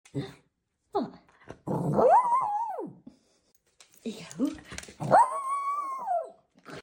Post walk woos from a happy sound effects free download